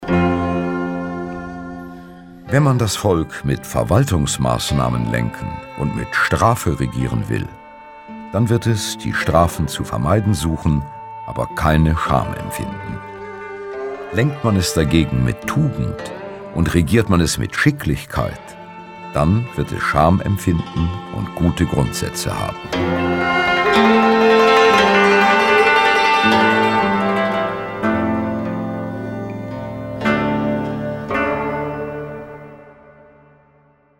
Trotz der Kompaktheit dieser Kulturgeschichte wirkt das weder anstrengend noch angestrengt, wozu auch der sachlich mit sonorer Stimme vortragende Rolf Becker entscheidend beiträgt. Hier wird kein magerer Abriss abgeliefert sondern ein kunstreiches Gewebe aus präzise ausgewählter Information, geschickt eingestreuten literarischen Perlen und pointierender Musik.